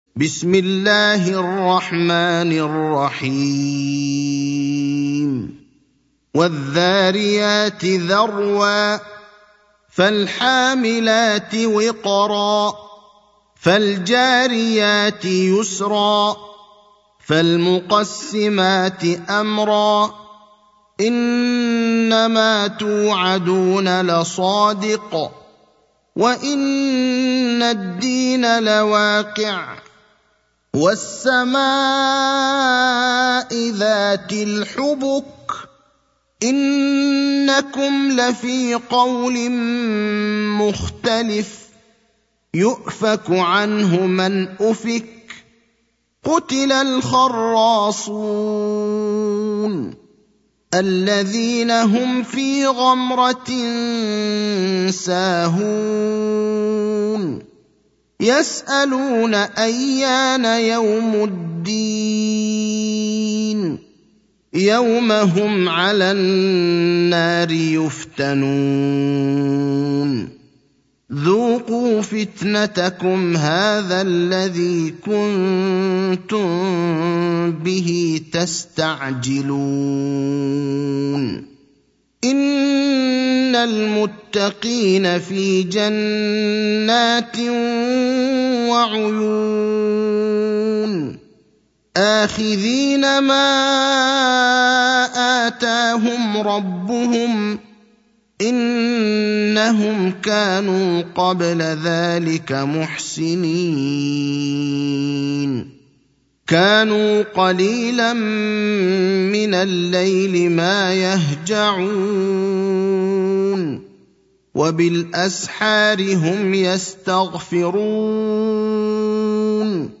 المكان: المسجد النبوي الشيخ: فضيلة الشيخ إبراهيم الأخضر فضيلة الشيخ إبراهيم الأخضر سورة الذاريات